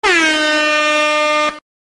Download Dancehall sound effect for free.
Dancehall